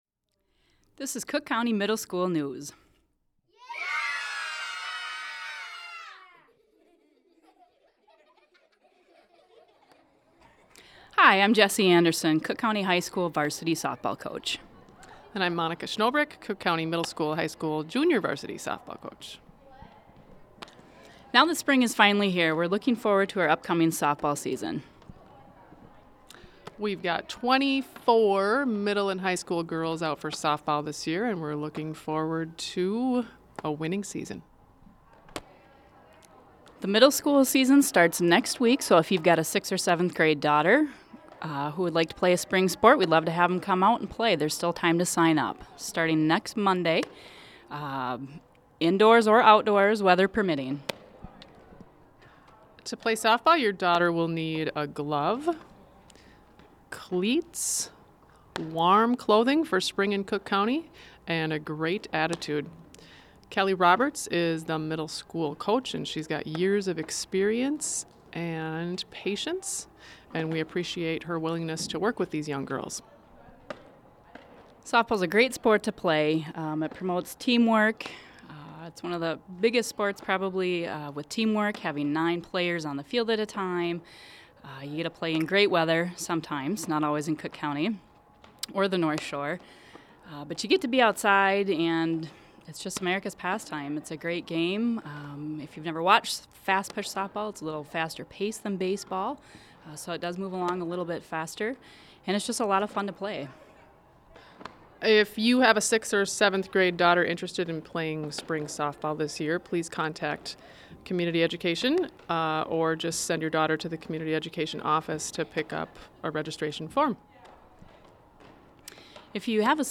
Attachment Size CC MS MP3 News 11 April 2013.mp3 7.02 MB Next week, Cook County Middle Schools girls softball begins. In this edition of School News, we hear from a few of the coaches about the sport and getting involved.